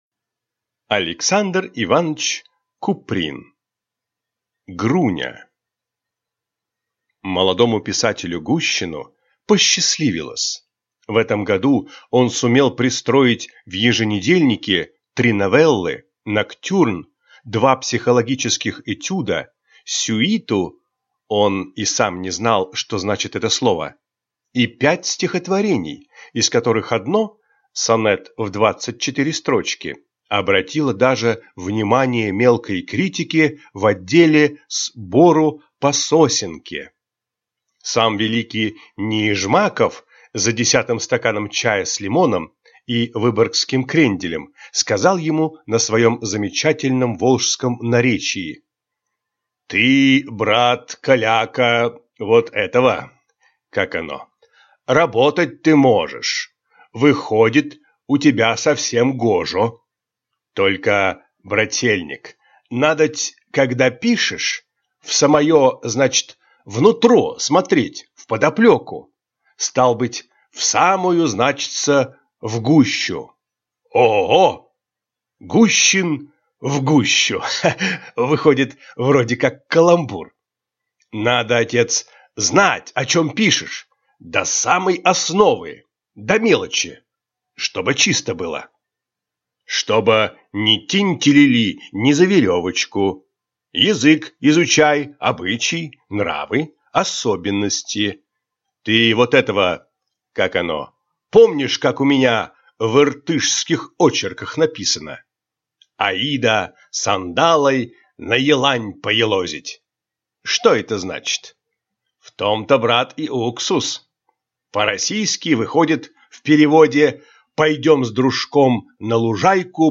Аудиокнига Груня